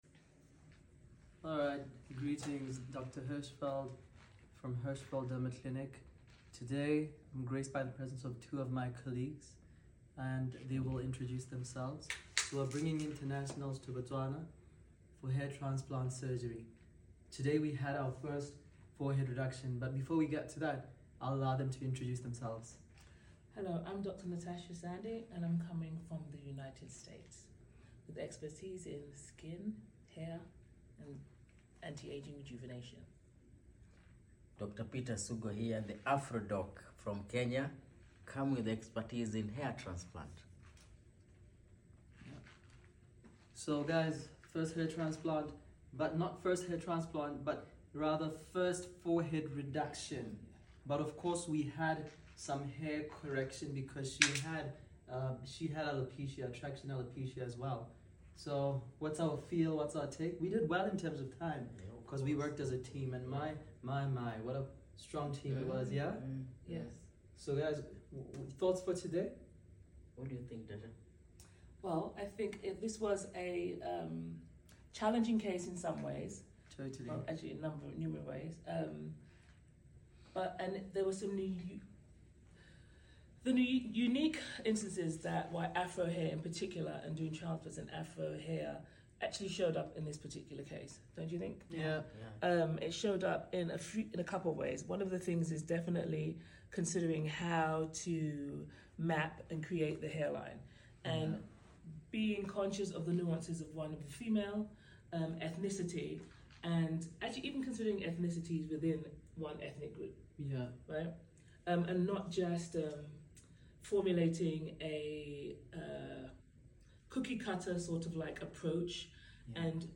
Deep conversation about African Hair